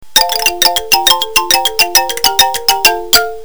ring2.mp3